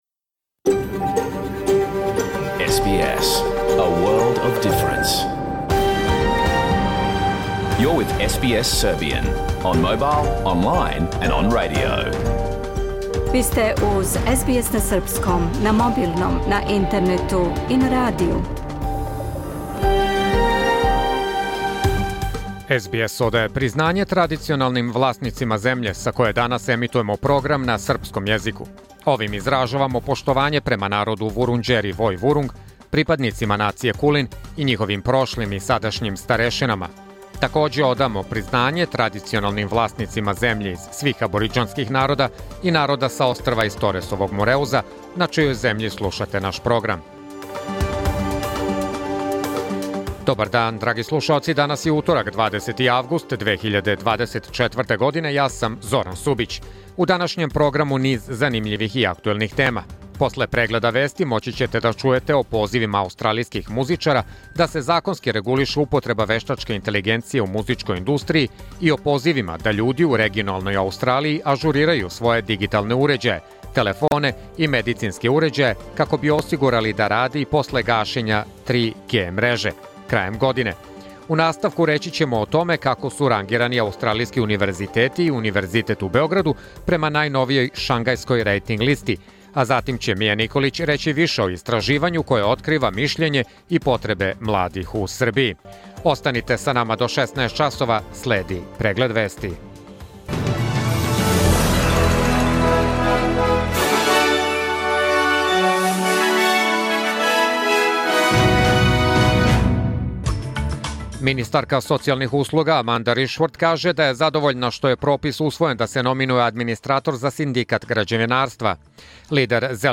Програм емитован уживо 20. августа 2024. године
Уколико сте пропустили данашњу емисију, можете је послушати у целини као подкаст, без реклама.